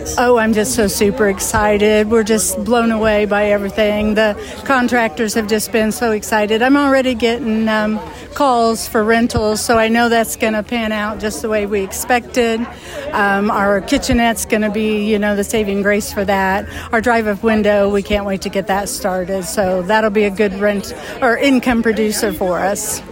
GRMC-RIBBON-CUTTING-CUT-1.mp3